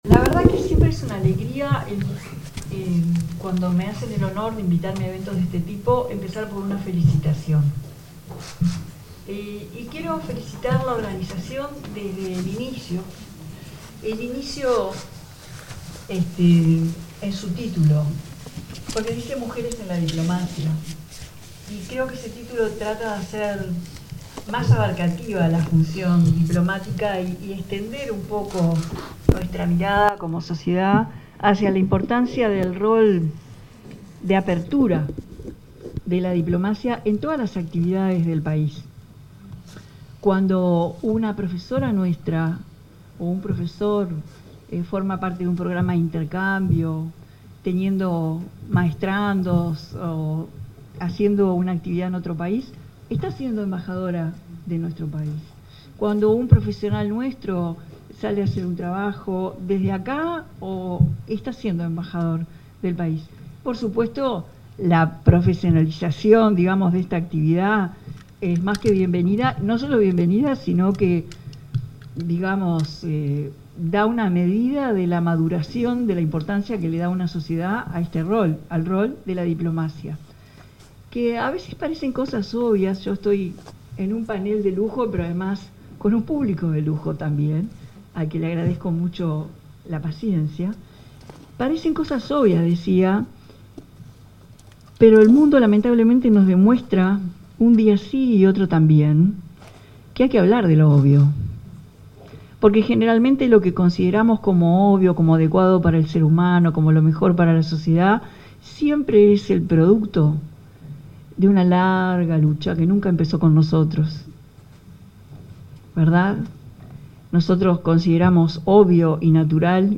Palabras de autoridades en Cancillería
Palabras de autoridades en Cancillería 02/07/2025 Compartir Facebook X Copiar enlace WhatsApp LinkedIn La presidenta de la República en ejercicio, Carolina Cosse; la ministra interina de Relaciones Exteriores, Valeria Csukasi, y la directora general adjunta para Asuntos Políticos de la Cancillería, Noelia Martínez, participaron en la conmemoración del Día Internacional de la Mujer en la Diplomacia.